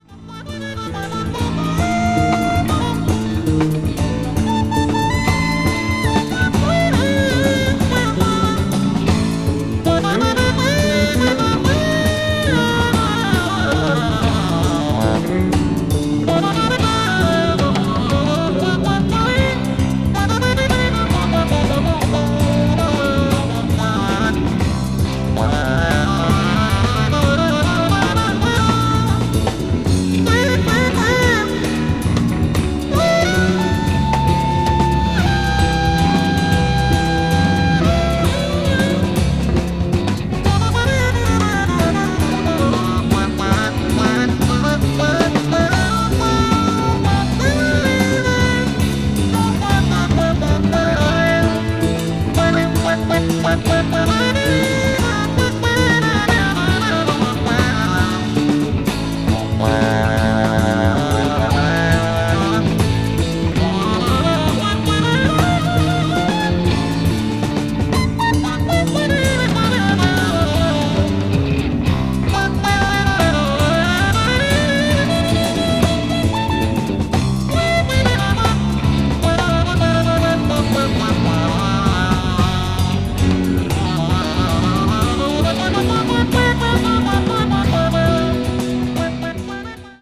contemorary jazz   deep jazz   jazz rock